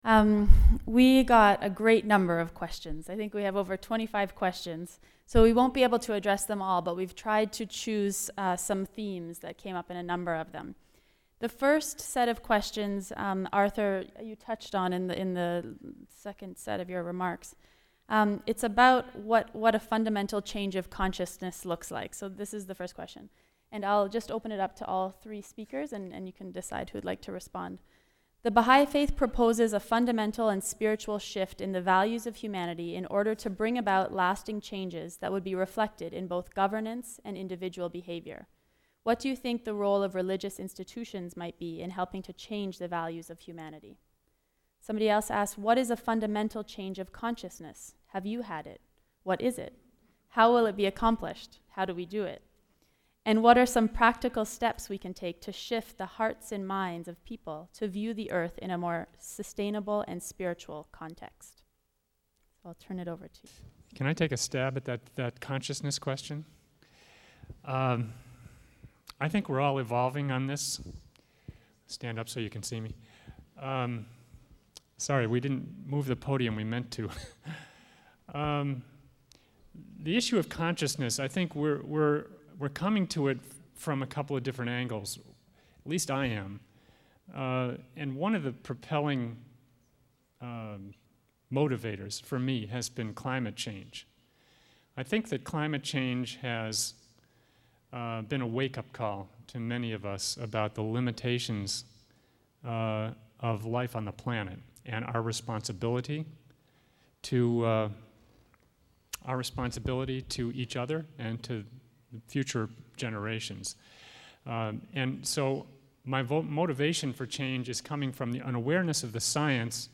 2. Question-and-answer session